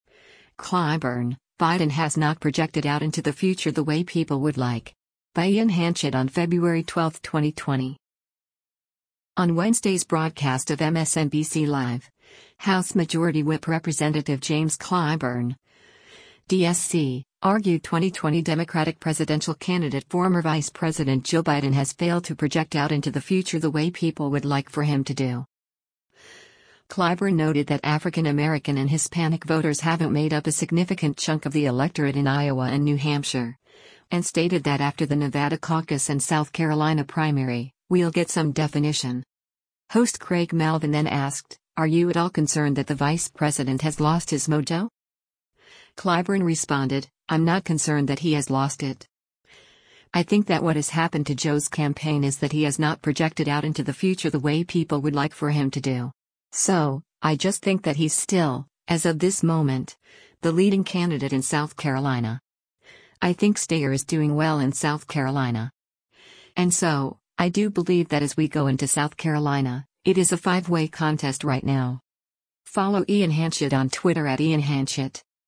On Wednesday’s broadcast of “MSNBC Live,” House Majority Whip Rep. James Clyburn (D-SC) argued 2020 Democratic presidential candidate former Vice President Joe Biden has failed to project “out into the future the way people would like for him to do.”
Host Craig Melvin then asked, “Are you at all concerned that the vice president has lost his mojo?”